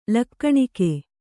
♪ lakkaṇike